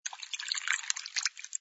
sfx_drinks_pouring05.wav